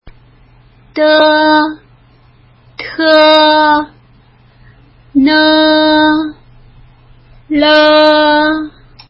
舌尖音
d(e) (無気音)舌先を上の歯茎にあて、弱い息で｢ド(ドとオの間)ー｣と発音するイメージ。
t(e) (有気音)舌先を上の歯茎にあて、強い息で｢トー｣と発音するイメージ。
n(e) 少し口を開いて、息を鼻から抜くように｢ノー｣と発音するイメージ。
l(e) 上の前歯に舌先をあてて、すぐに離す感じで、｢ロー｣と発音するイメージ。